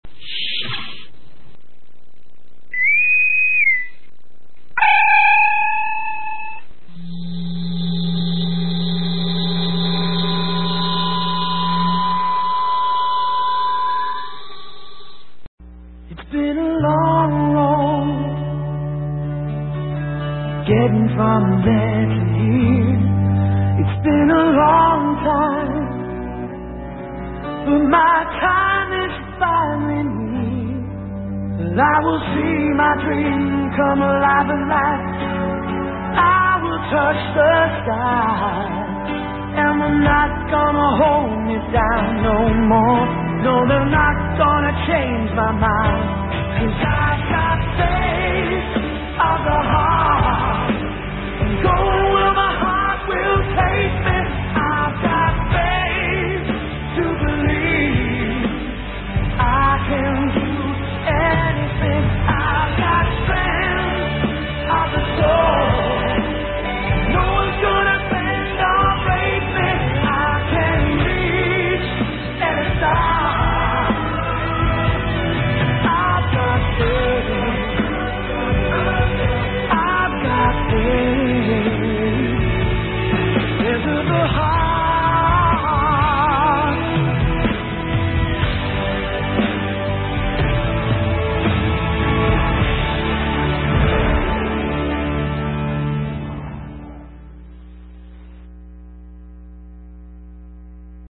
theme song
sounds transporter door swish Boatswain whistle